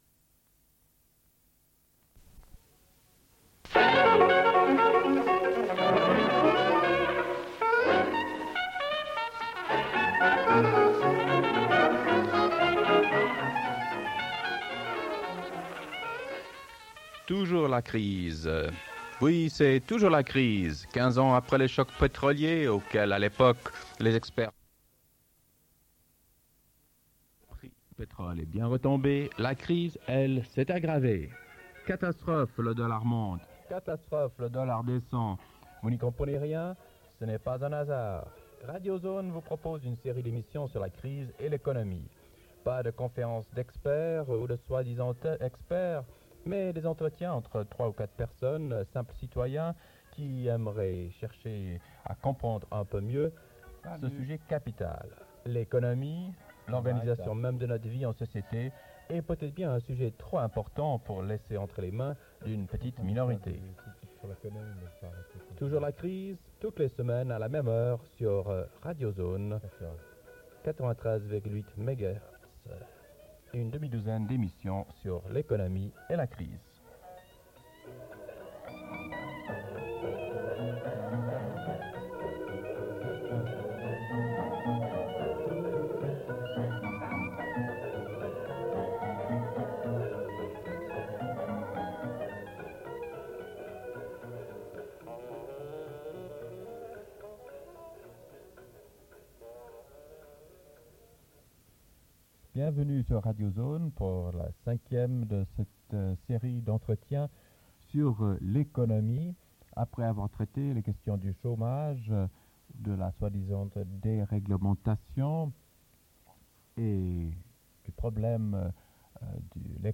Dès 00:32:45, enregistrement d'entretien téléphonique au sujet de la centrale de Creys-Malville, à propos du redémarrage de Superphénix. Cette deuxième partie semble dater du mois de novembre et a probablement été enregistrée par dessus le bulletin d'information. Dès 00:40:05 : Bulletin d'information de Radio Zones.